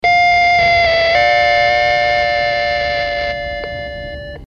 Elevator sound in MP3 :)
heis-ding.mp3